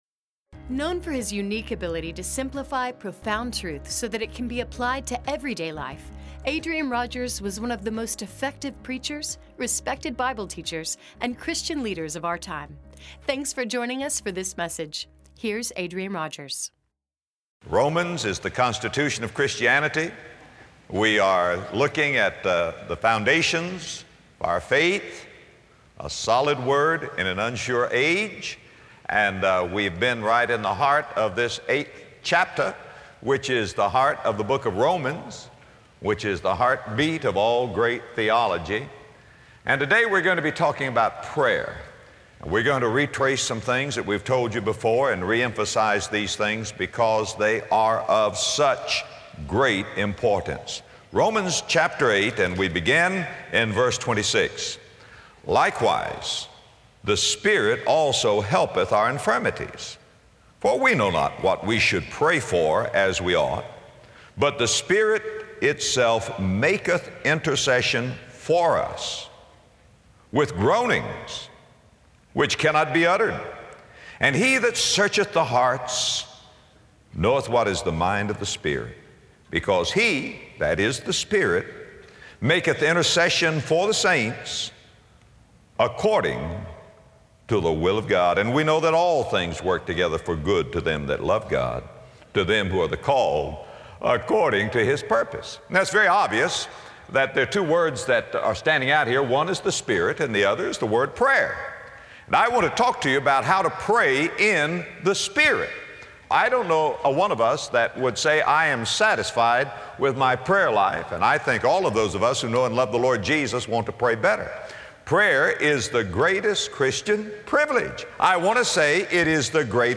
As Christians, prayer is our greatest privilege, yet our greatest failure; knowing this, God has given us a great Helper. In this message from Romans 8, Adrian Rogers reveals how the Holy Spirit helps us in prayer.